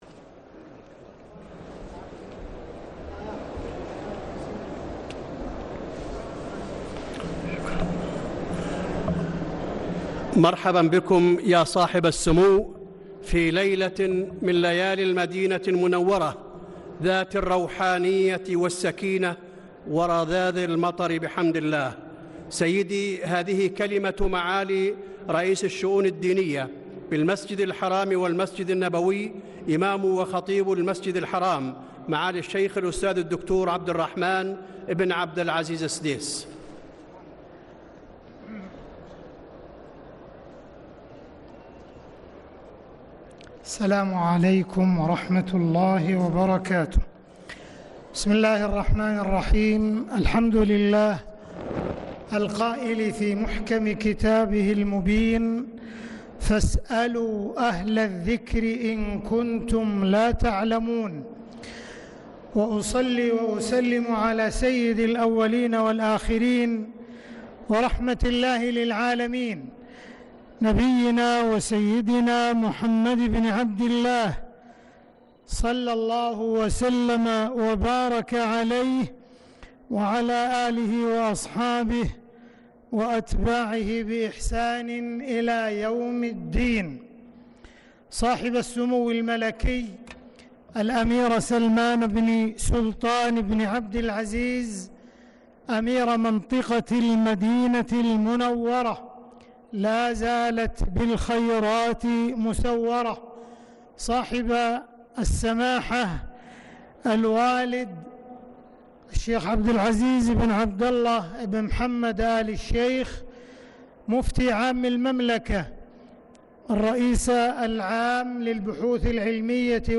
كلمة الشيخ عبدالرحمن السديس في حفل افتتاح ندوة الفتوى في الحرمين الشريفين 23 صفر 1446هـ > ندوة الفتوى في الحرمين الشريفين > المزيد - تلاوات الحرمين